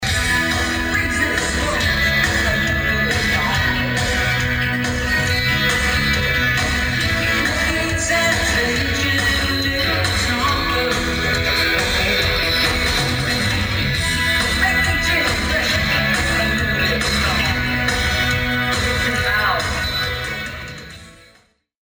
Freeze this Vegas moment